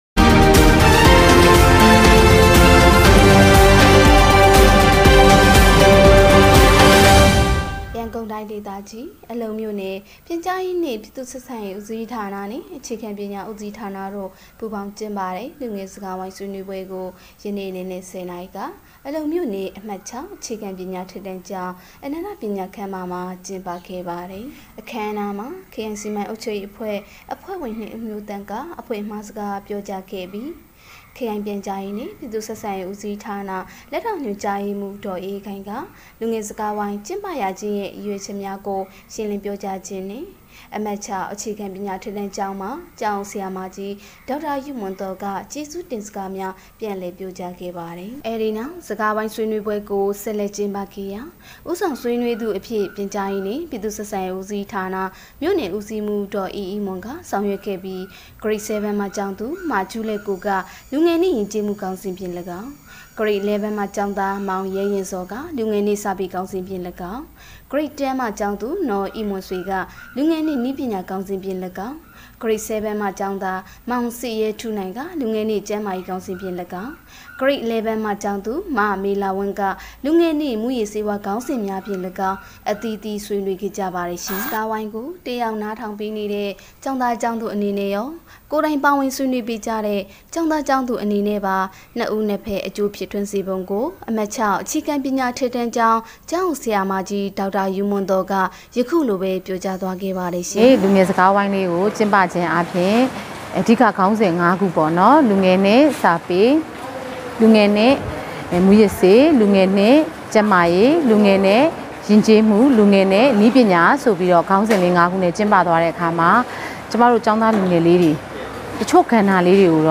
အလုံမြို့နယ်၌ လူငယ်စကားဝိုင်းဆွေးနွေးပွဲ ကျင်းပ